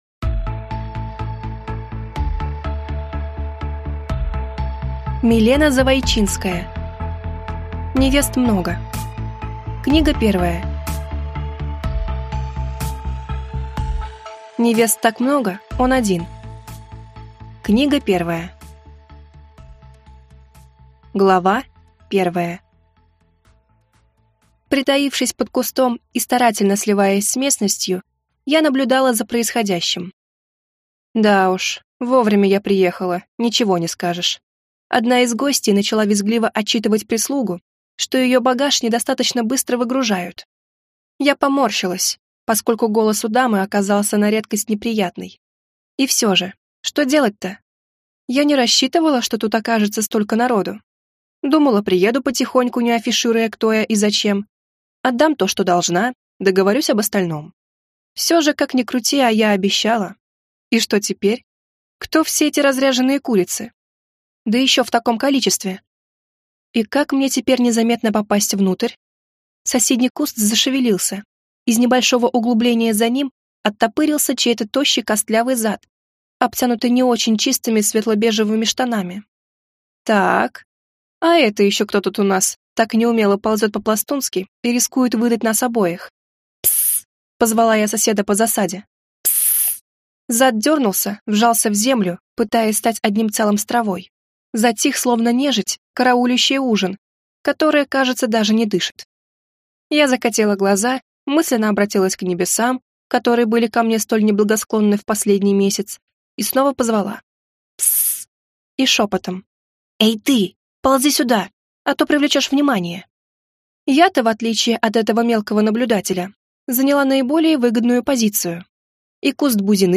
Аудиокнига Невест так много, он один. Книга 1 | Библиотека аудиокниг